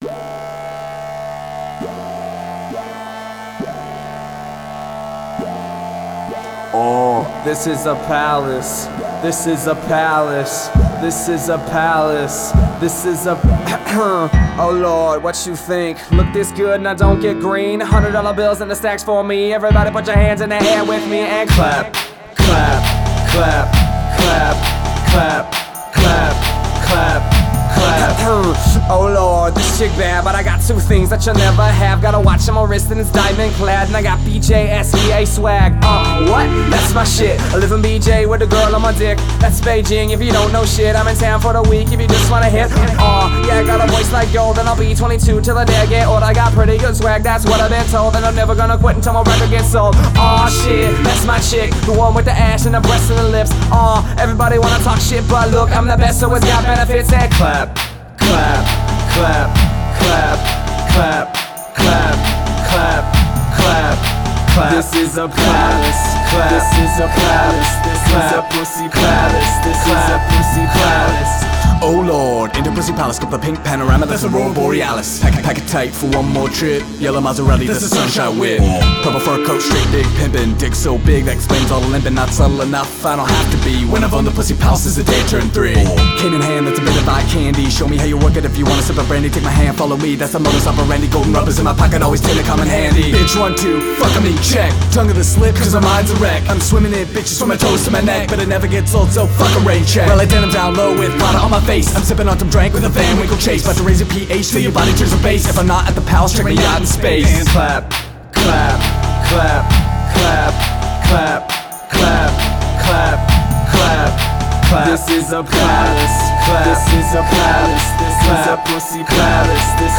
local hip-hop artists